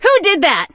max_hurt_vo_03.ogg